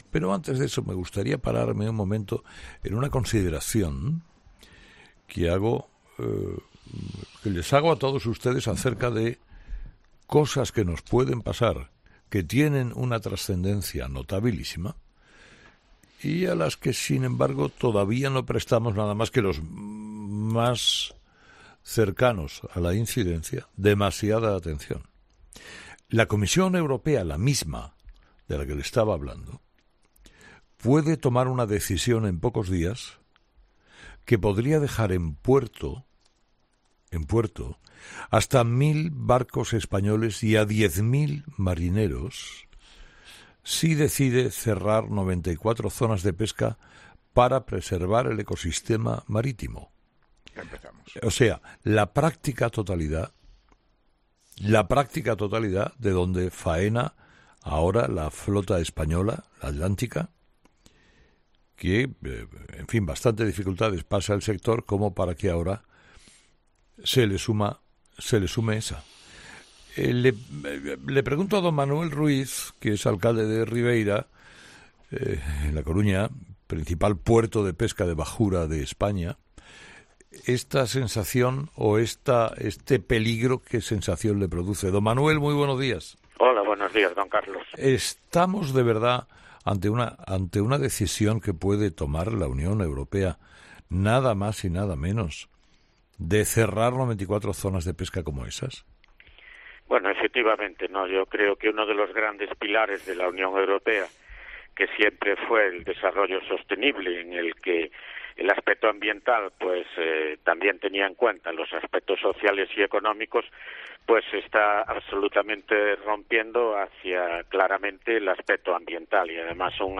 En 'Herrera en COPE' hablamos con Manuel Santos Ruiz Rivas, alcalde de Ribeira (A Coruña), sobre el posible veto de la Comisión Europea para la pesca...
Entrevista a Manuel Santos Ruiz Rivas, alcalde de Ribeira (A Coruña)